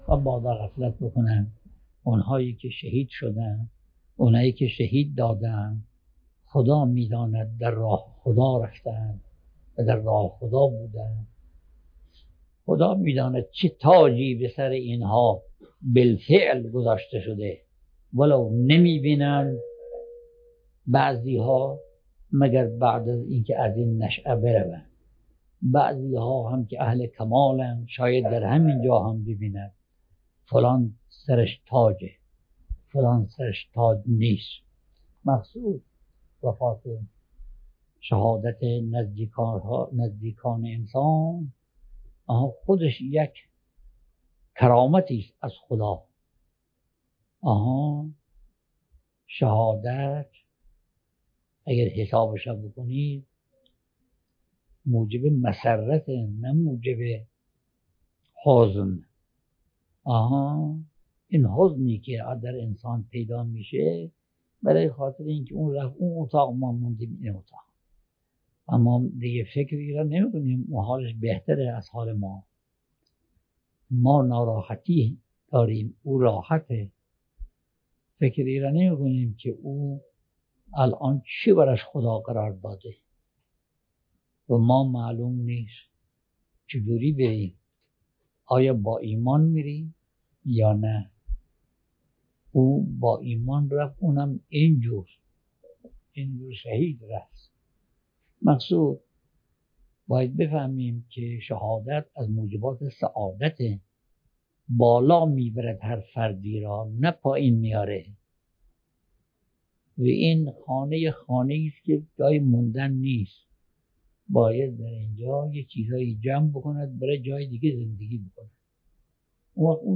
سخنرانی‌ها
بیاناتی در دیدار با خانواده شهدا